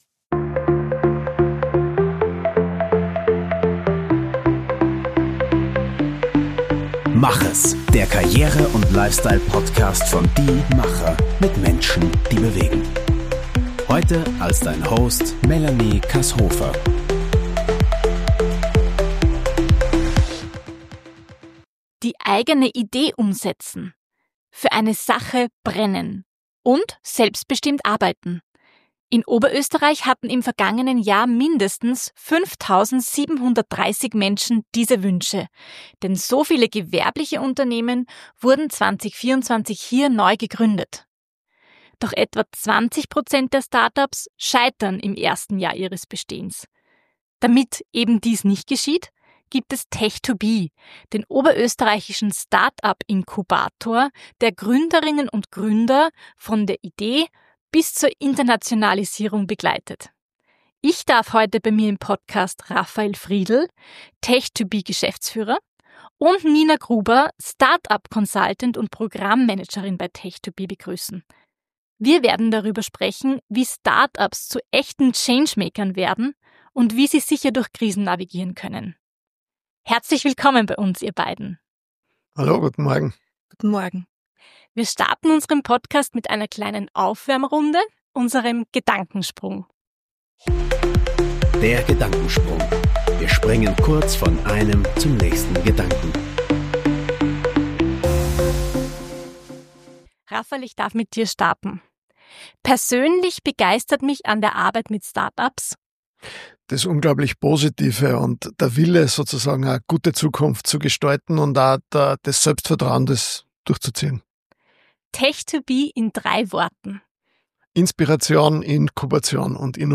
Unser Podcast ist in 3 Teile aufgeteilt: Einleitung, Wordrap als Gedankensprung und Interview.